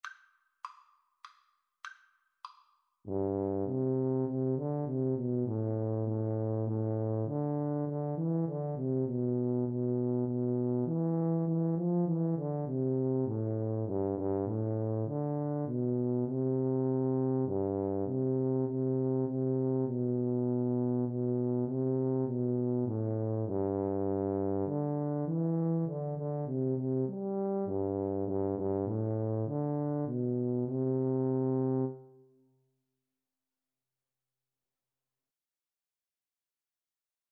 C major (Sounding Pitch) (View more C major Music for Tuba Duet )
3/4 (View more 3/4 Music)
Moderato
Tuba Duet  (View more Easy Tuba Duet Music)